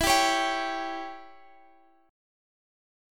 Listen to Em6 strummed